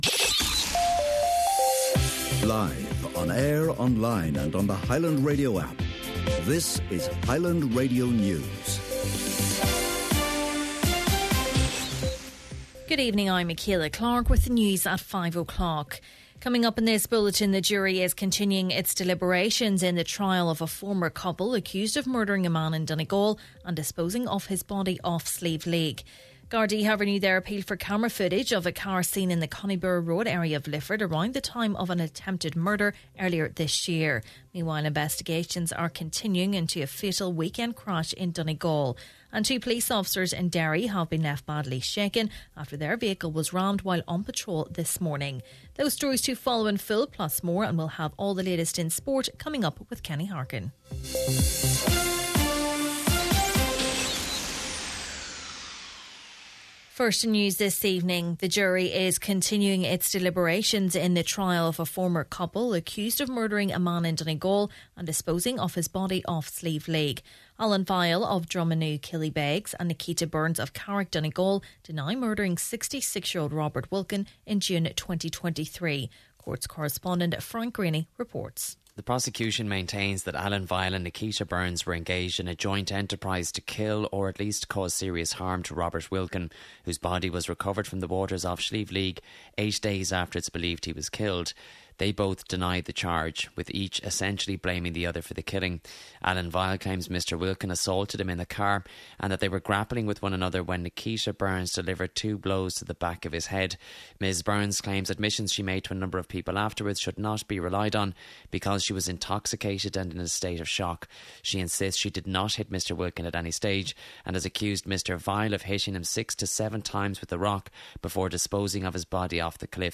Main Evening News, Sport and Obituaries – Tuesday, February 4th